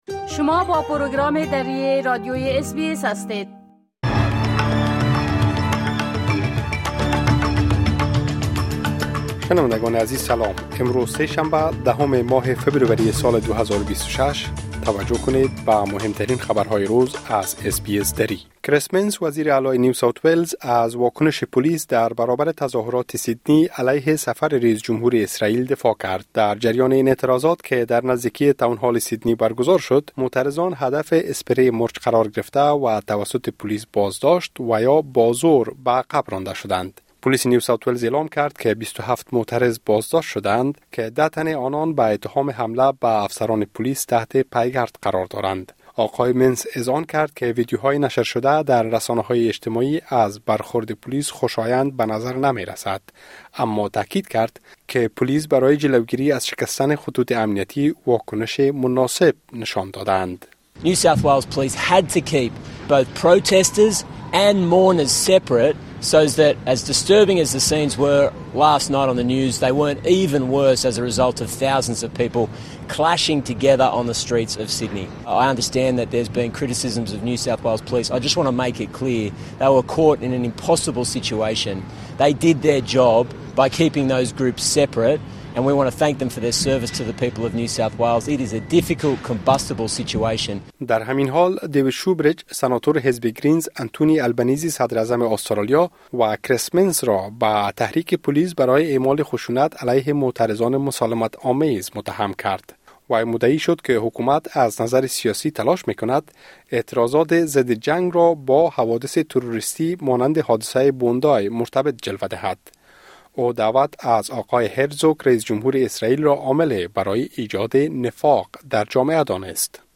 مهم‌ترين خبرهای روز | ۱۰ فبروری